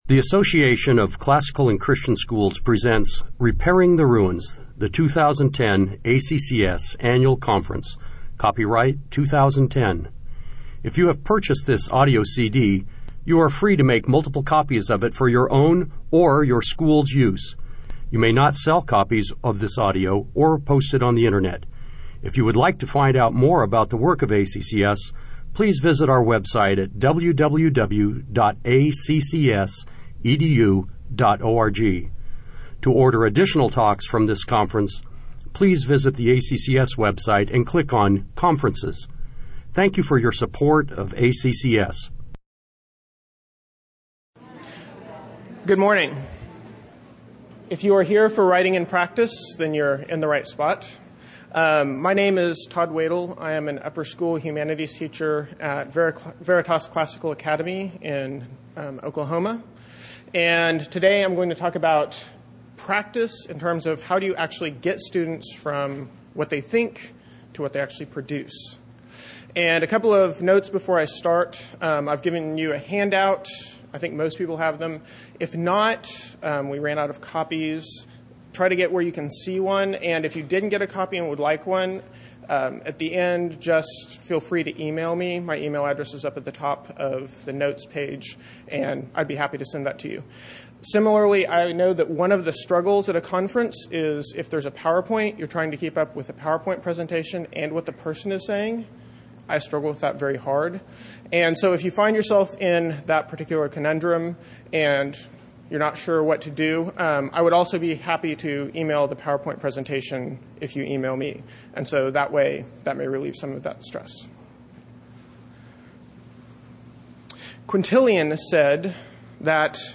2010 Workshop Talk | 0:59:06 | All Grade Levels, Rhetoric & Composition
The Association of Classical & Christian Schools presents Repairing the Ruins, the ACCS annual conference, copyright ACCS.